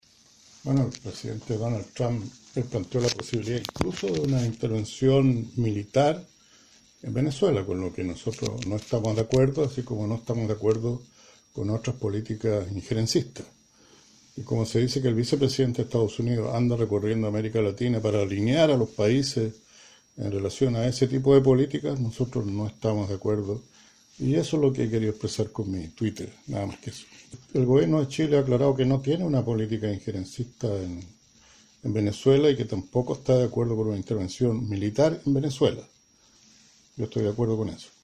Al respecto, el Presidente del Partido Comunista, diputado Guillermo Teillier ante visita de Mike Pence afirmó: